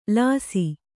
♪ lāsi